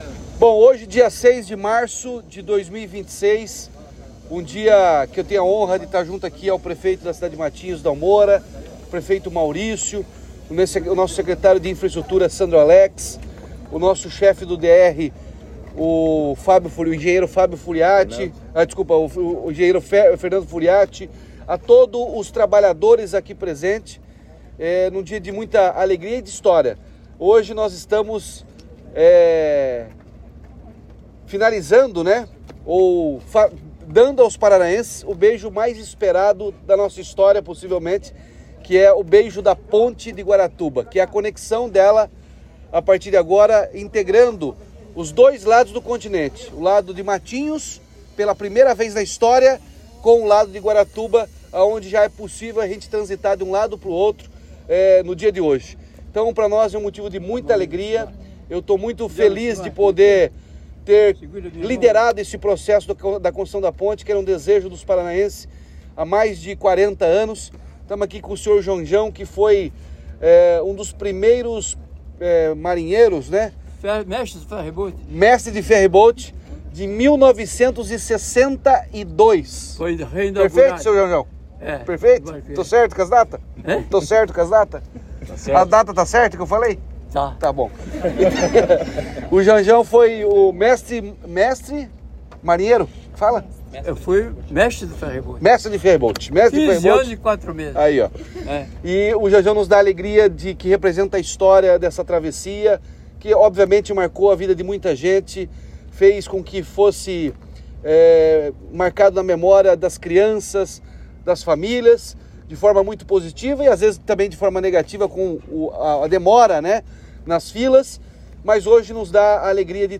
Sonora do governador Ratinho Junior sobre o "beijo" da Ponte de Guaratuba